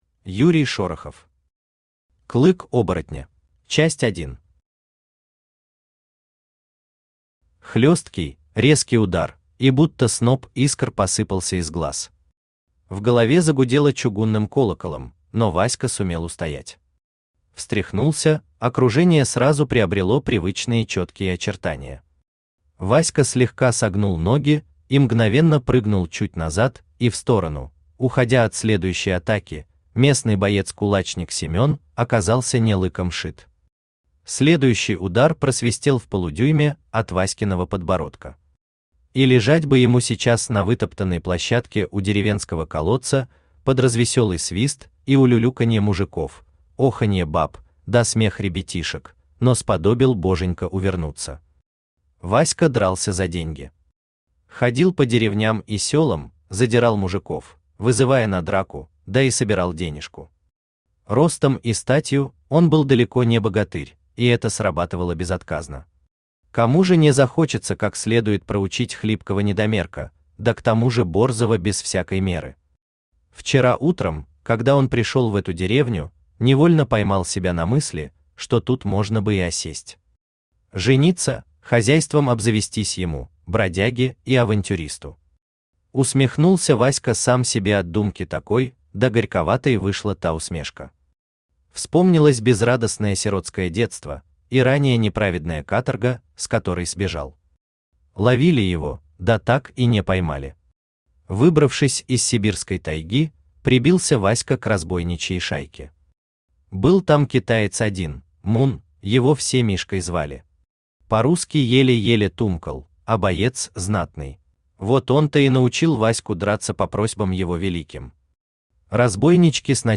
Аудиокнига Клык оборотня | Библиотека аудиокниг
Aудиокнига Клык оборотня Автор Юрий Шорохов Читает аудиокнигу Авточтец ЛитРес.